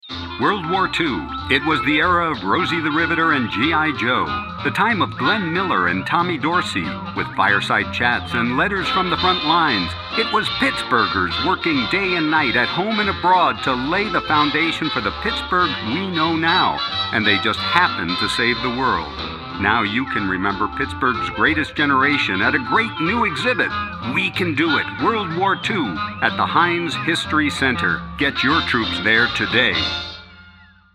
We Can Do It! WWII radio spot
We-Can-Do-It-Radio-Spot-FINAL.mp3